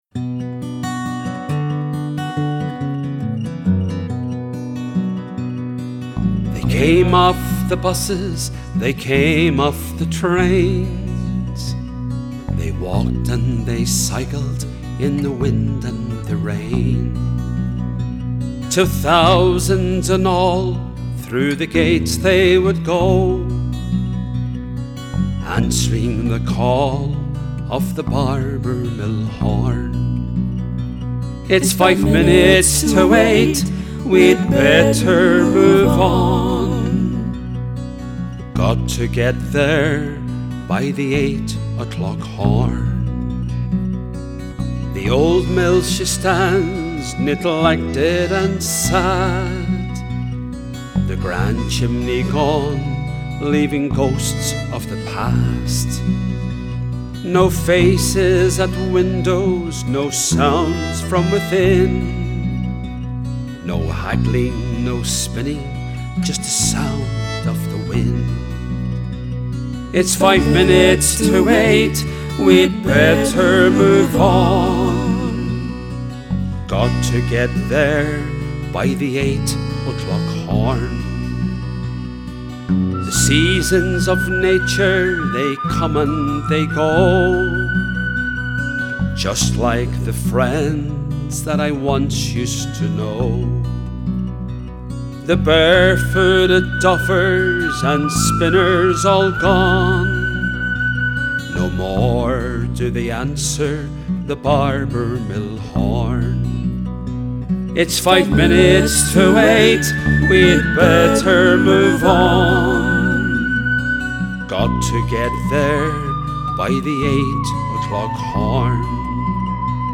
Ask the pupils, working in pairs, to listen and identify the words and phrases that create a sad feeling;
Resource 5.13: Barbour Mill (MP3)